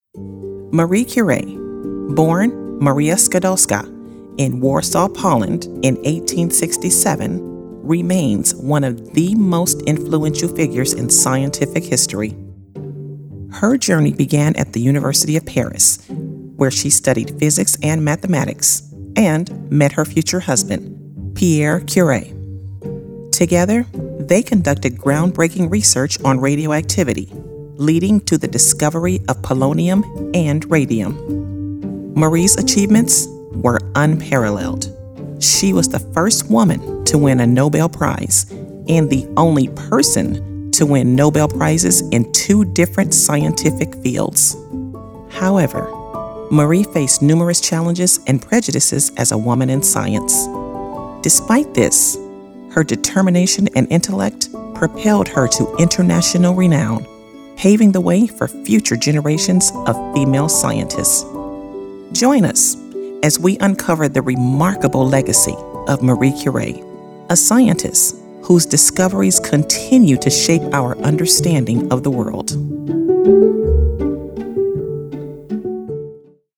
Female
Adult (30-50), Older Sound (50+)
My voice is authentic, smooth and can be raspy at times. It is professional, articulate, trustworthy, assertive, warm, inviting and believable. It's also quite soothing.
Narration
Words that describe my voice are Warm, Believable, Professional.